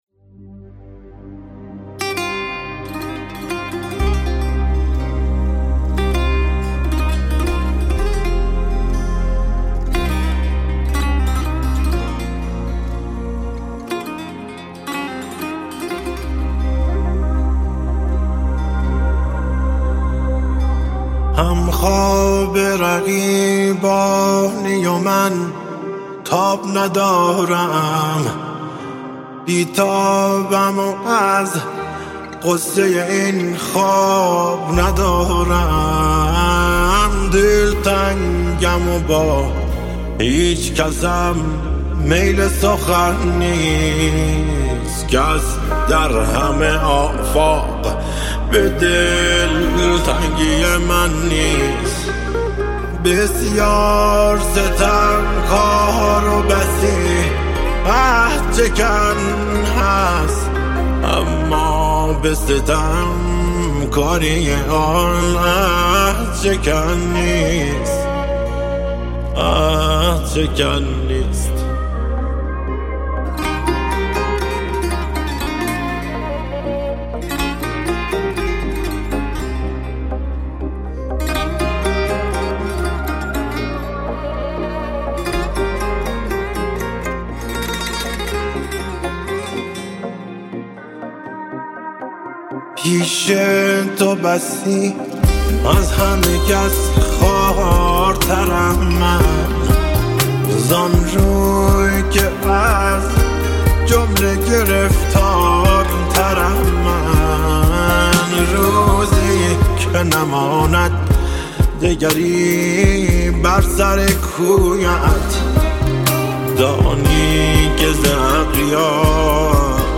شاد
عاشقانه